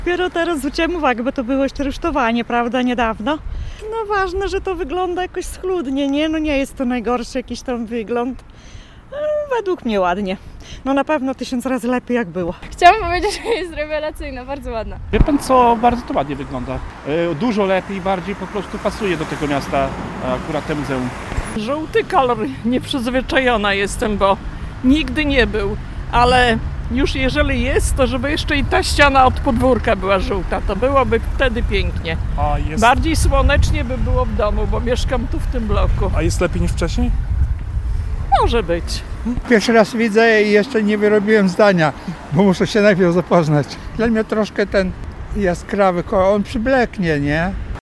W środę (20.11.24), przed suwalską Resursą pytaliśmy przechodniów, co myślą o nowej kolorystyce.